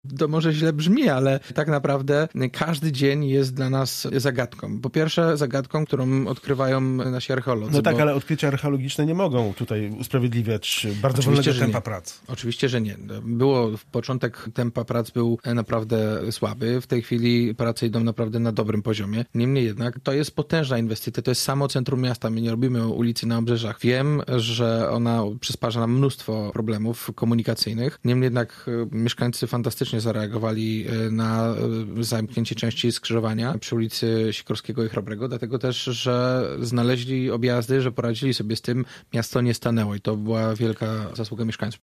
Ostatecznego terminu zakończenia prac, nie był w stanie określić nawet prezydent Gorzowa Jacek Wójcicki, który był dziś porannym gościem w Radiu Gorzów: